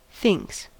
Uttal
Uttal US Ordet hittades på dessa språk: engelska Ingen översättning hittades i den valda målspråket.